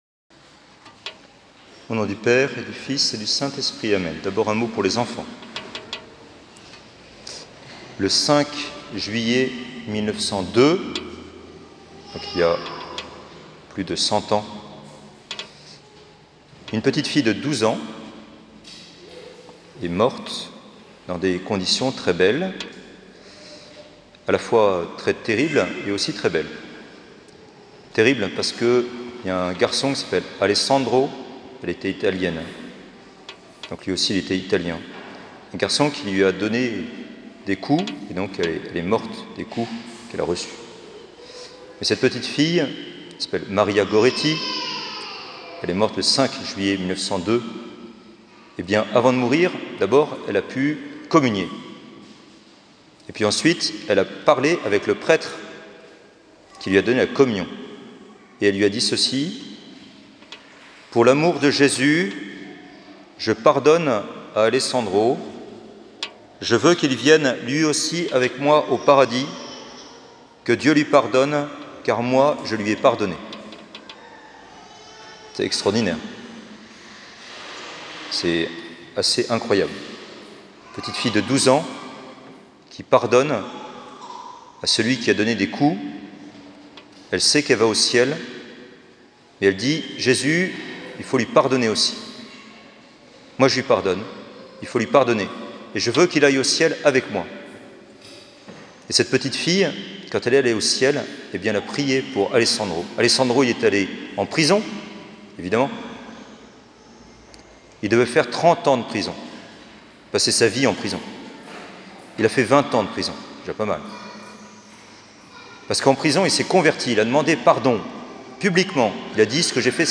Église catholique Saint-Georges à Lyon
Homélies du dimanche